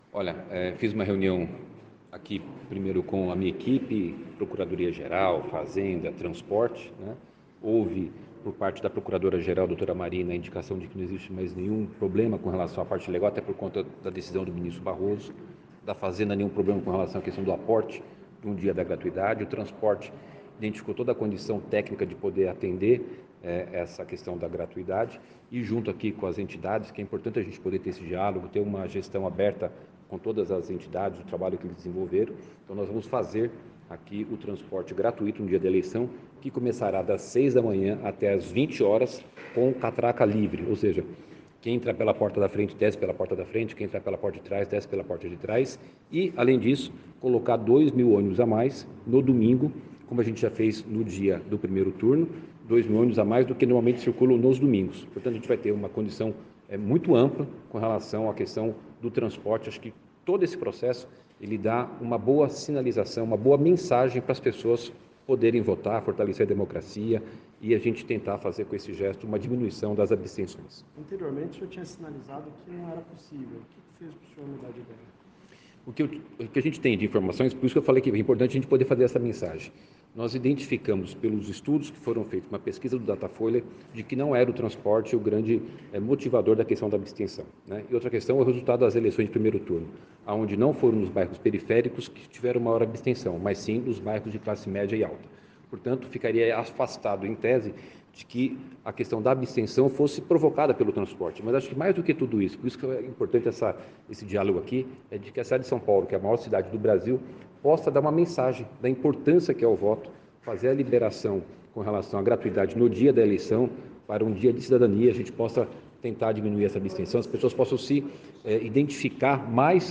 Ouça a entrevista do prefeito Ricardo Nunes neste link:abaixo: